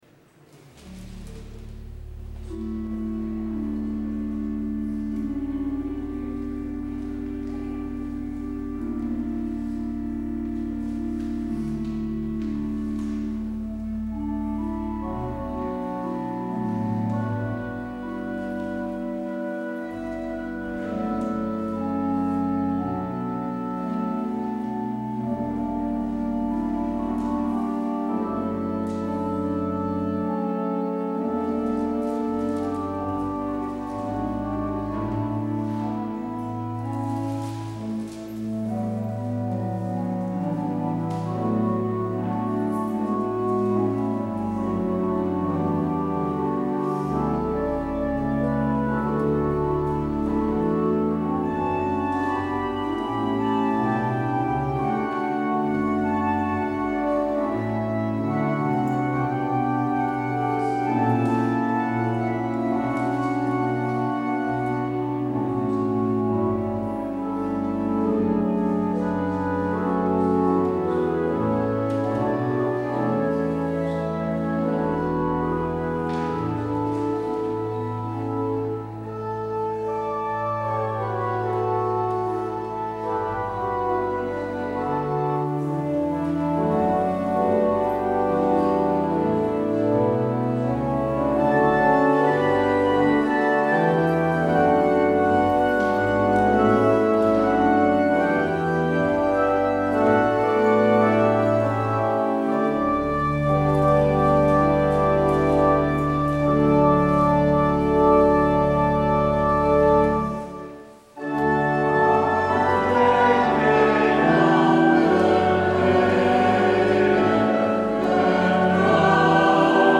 Het openingslied is Heer, raak mij aan met uw adem (Lied 695: 1, 2, 5).